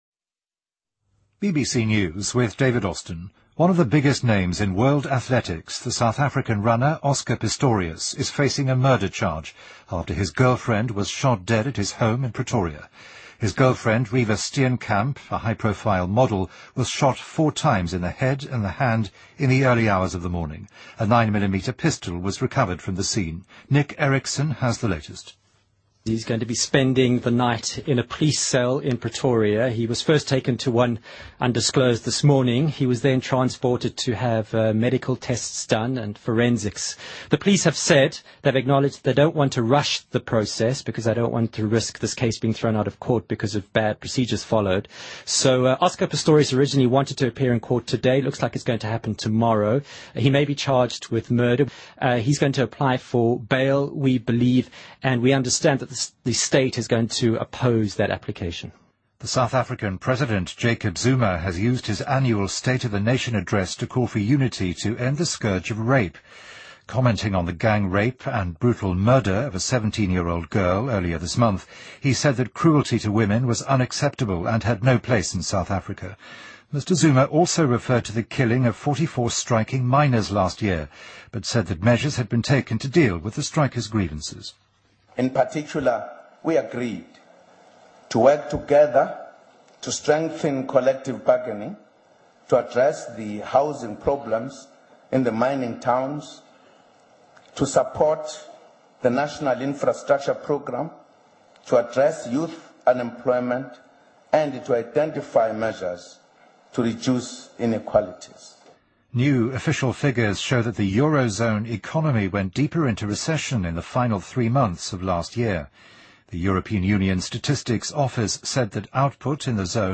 BBC news,沃伦·巴菲特商业财团称将购买亨氏食品公司